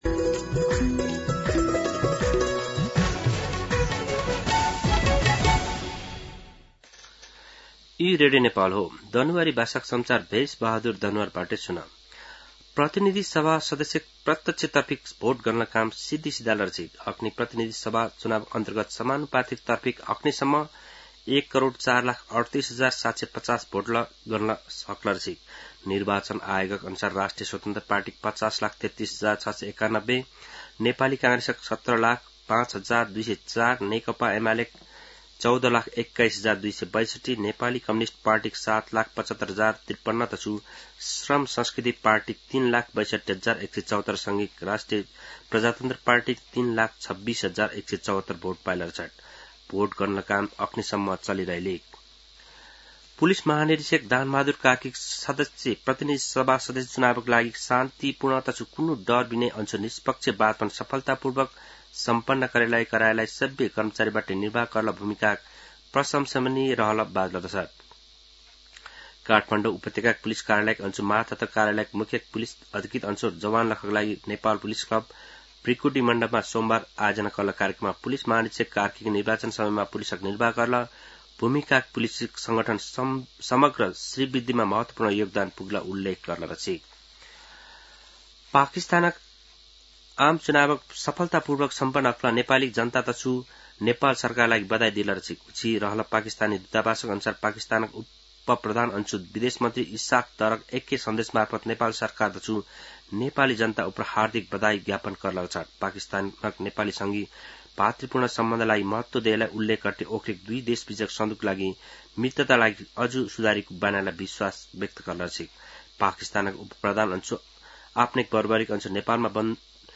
दनुवार भाषामा समाचार : २६ फागुन , २०८२
Danuwar-News-26.mp3